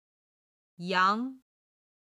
6位　杨　(yáng)　楊　ヤン